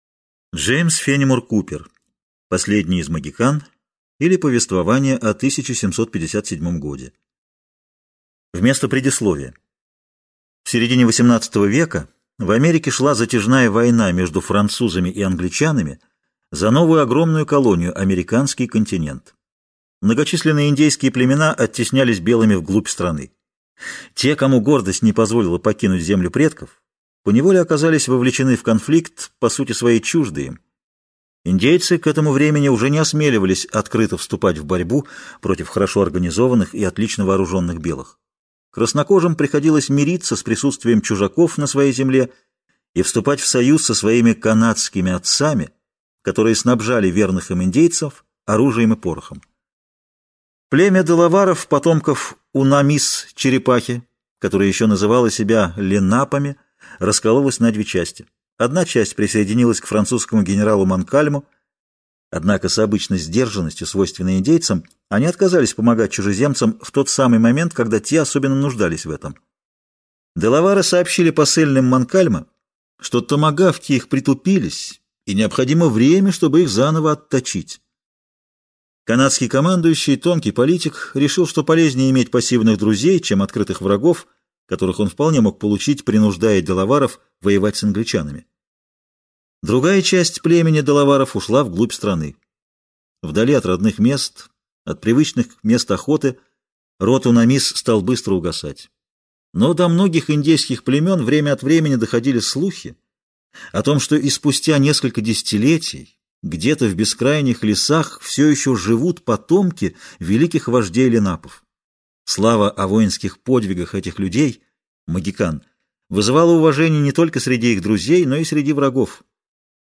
Аудиокнига Последний из Могикан (сокращенный пересказ) | Библиотека аудиокниг